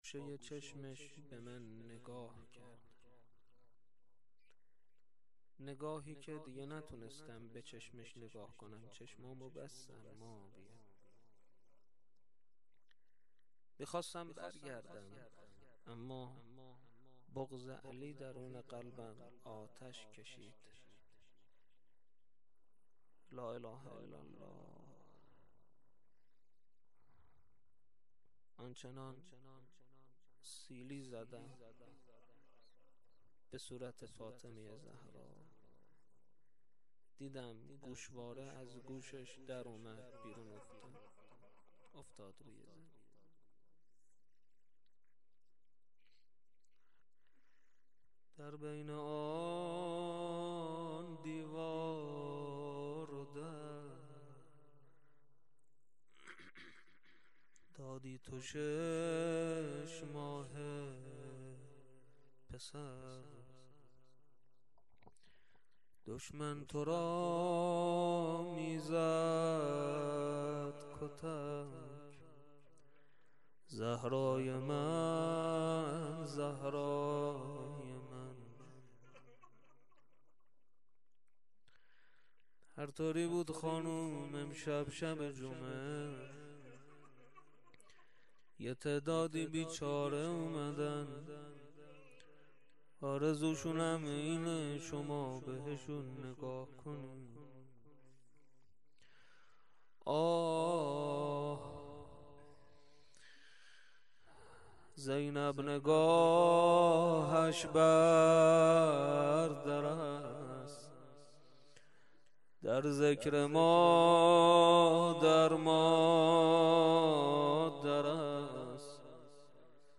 rozeh.mp3